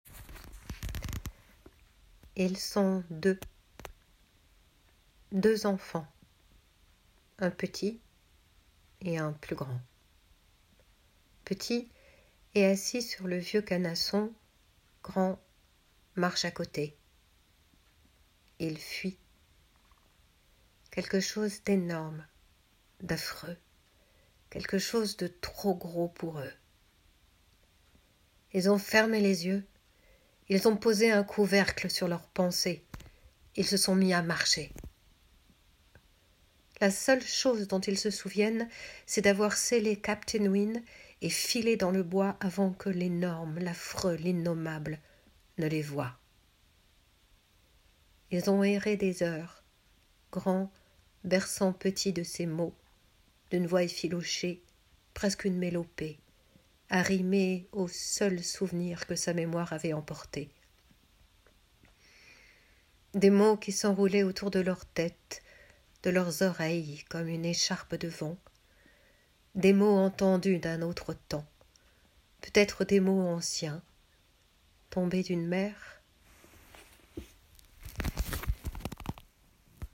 Narratrice
34 - 50 ans - Mezzo-soprano